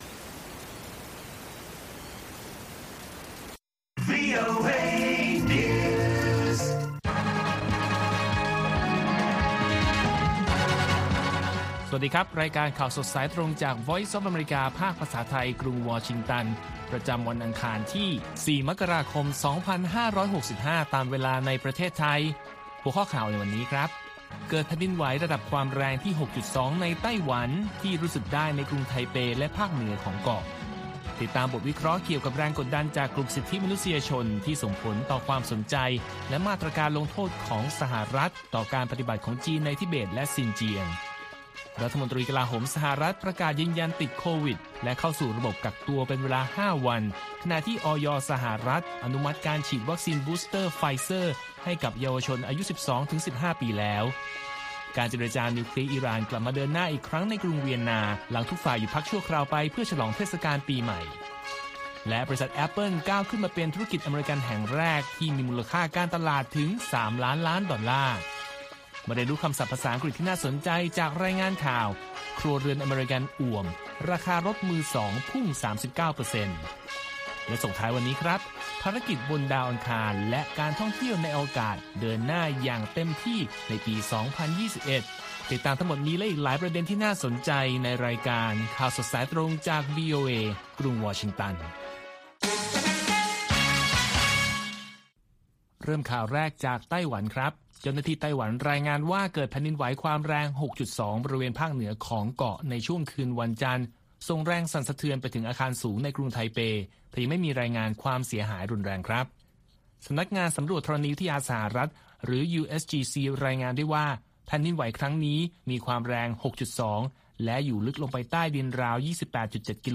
ข่าวสดสายตรงจากวีโอเอ ภาคภาษาไทย ประจำวันอังคารที่ 4 มกราคม 2565 ตามเวลาประเทศไทย